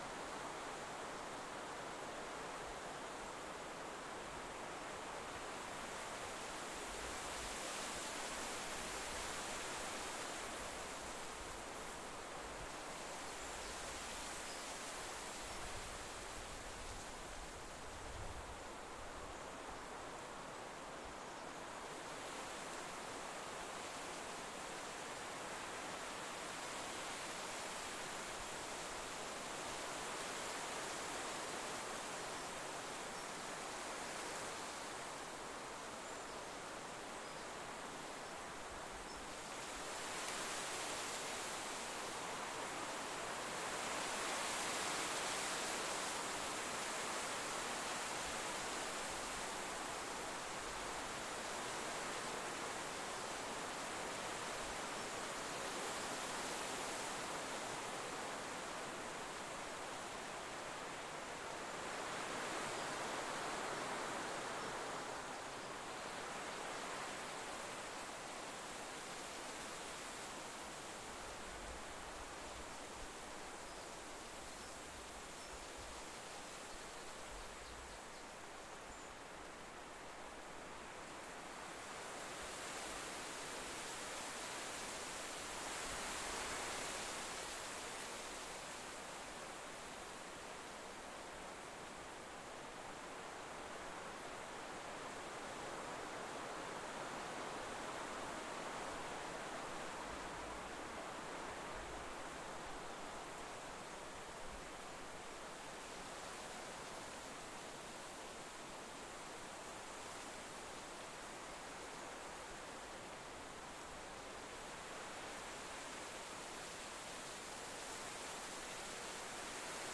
ambienceWind1SeamlessMono.ogg